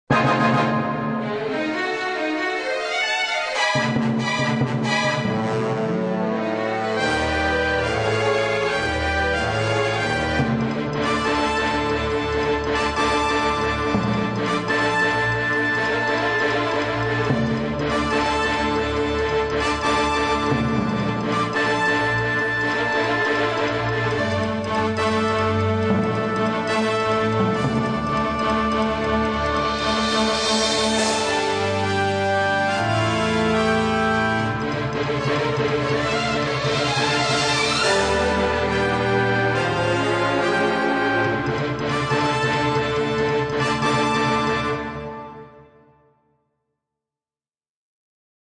~ САУНДТРЕК ~